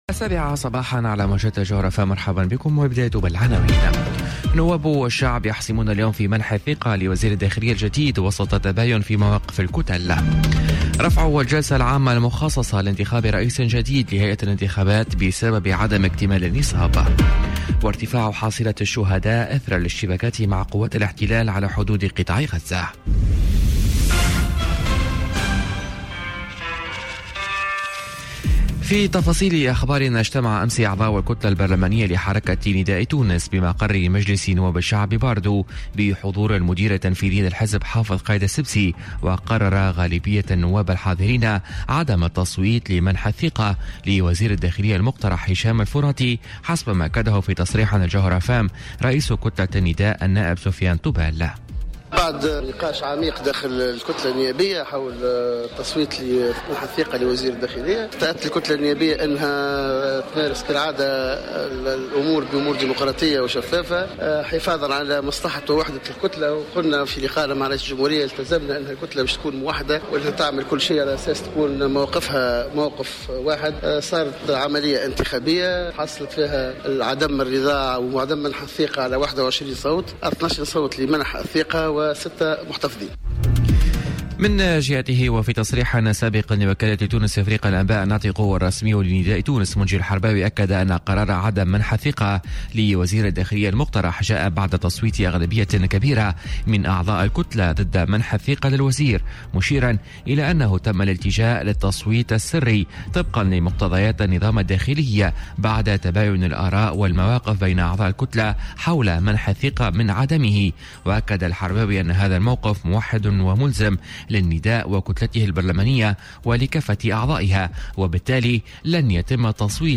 نشرة أخبار السابعة صباحا ليوم السبت 28 جويلية 2018